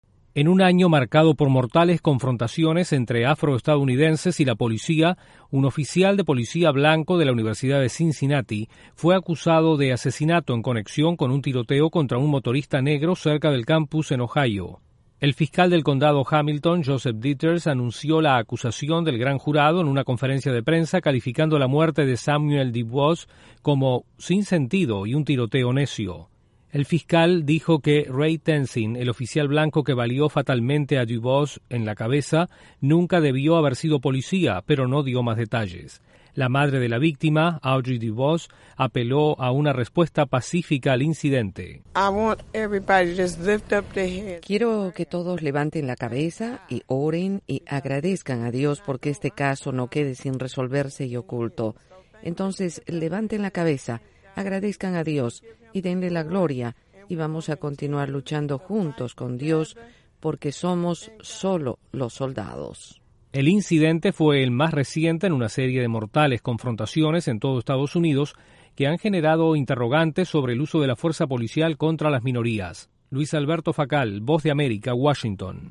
En otro mortal incidente entre un afro estadounidense y la policía en EE.UU. un oficial es acusado de asesinato. Desde la Voz de América en Washington informa